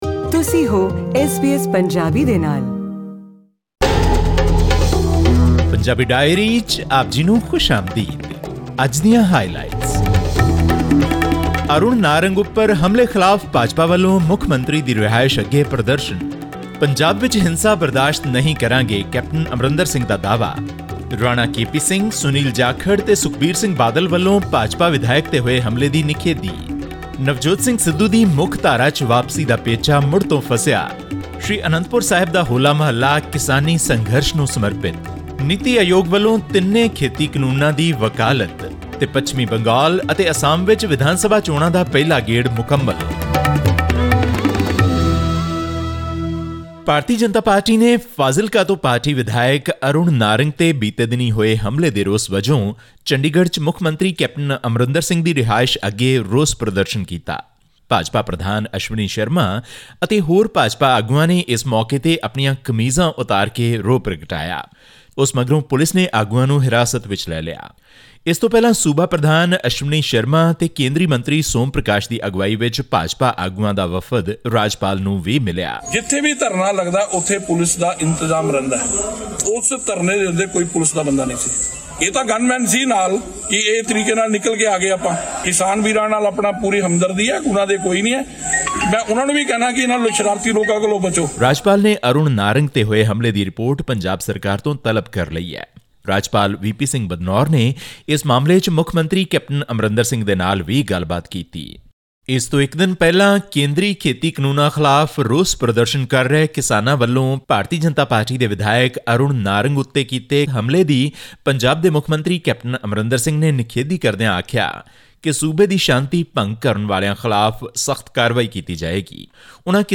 Bharatiya Janata Party (BJP) workers led by state chief Ashwani Sharma staged a protest outside the official residence of Chief Minister Amarinder Singh to protest against the attack on party MLA Arun Narang in Muktsar district on Sunday. This and more in our weekly news segment from Punjab.